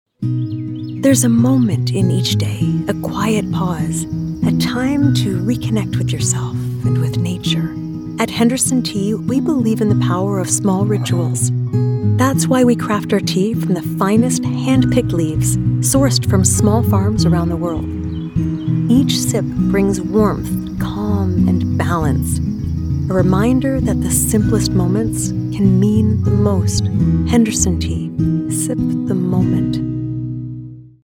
Commercial Sample (EN)
Clients describe her sound as engaging, distinct, and easy on the ears - like a trusted guide who knows how to have fun.
Broadcast-quality home studio | Fast, reliable turnaround | Friendly, professional, and directable